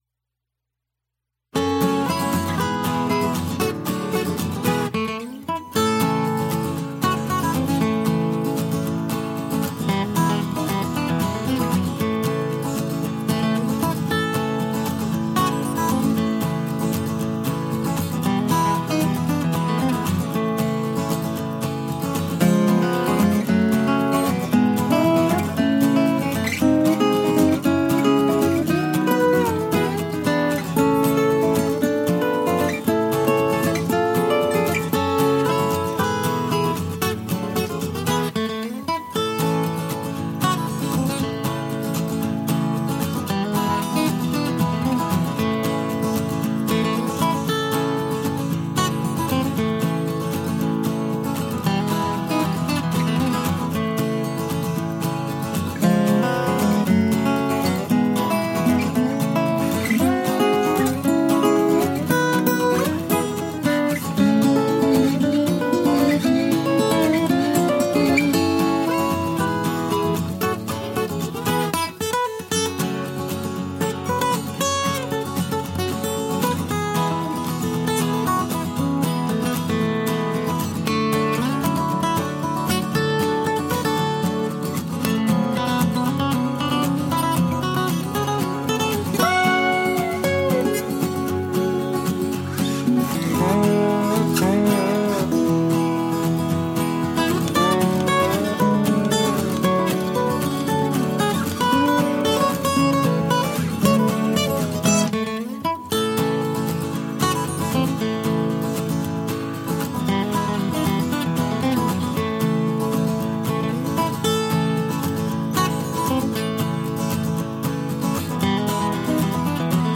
描述：国家/地区和民族|明快
Tag: 原声吉他 弦乐器